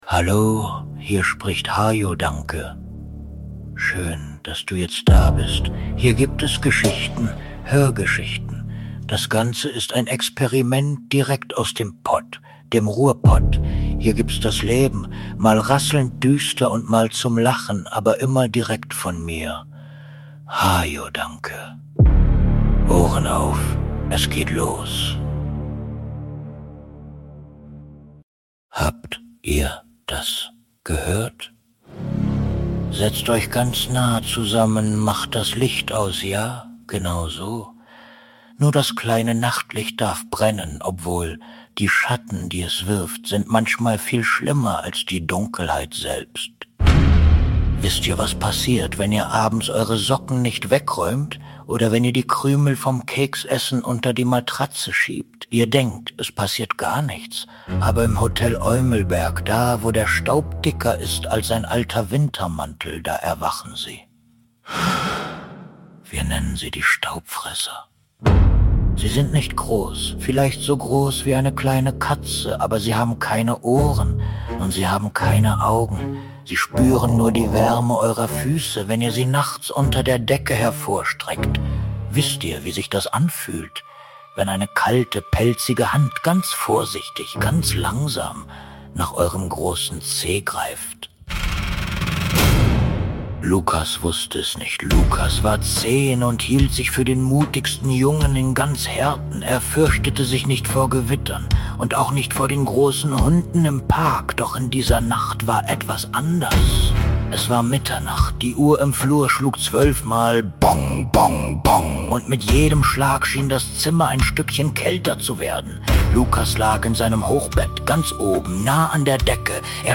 Das Flüstern unter dem Hochbett - Grusel (Hör)Geschichten für junge Entdecker ab ca. 6 Jahren